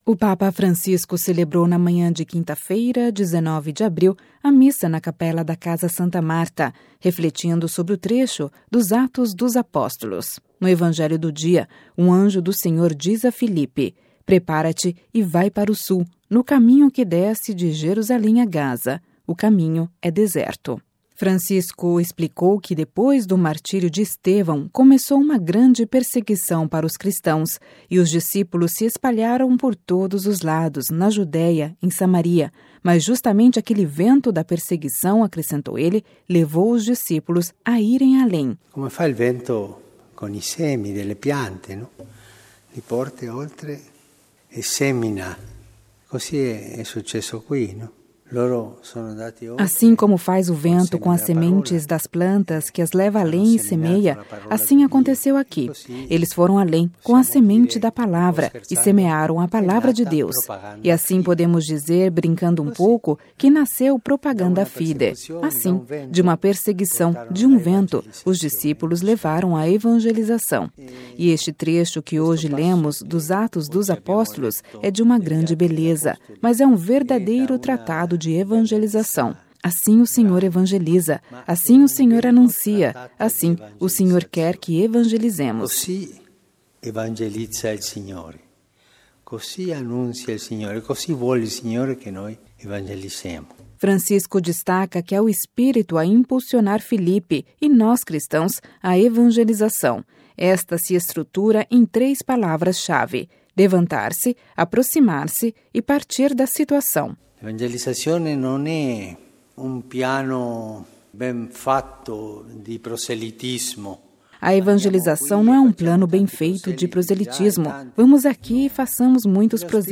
O Papa Francisco celebrou na manhã de quinta-feira (19/04) a missa na capela da Casa Santa Marta, refletindo sobre o trecho dos Atos dos Apóstolos.
Ouça a reportagem com a voz do Papa Francisco: